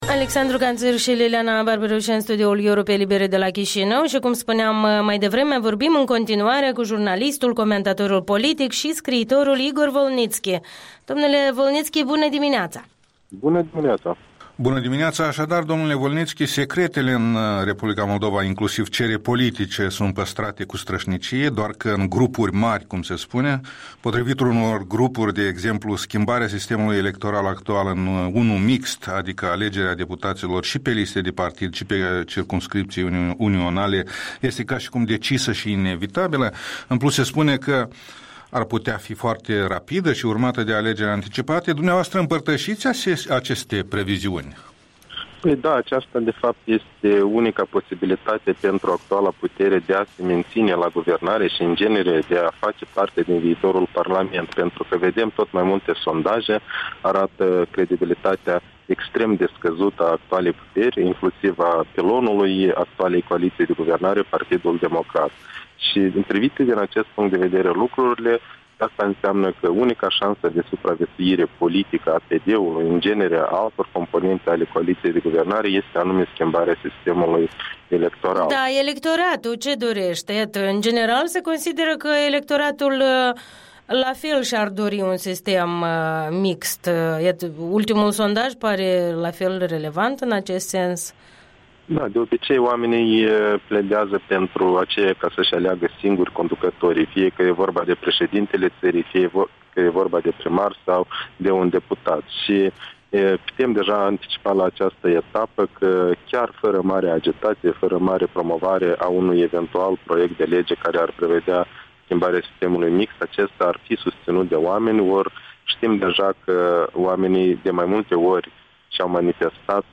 Interviul dimineții cu analistul politic de la Chișinău.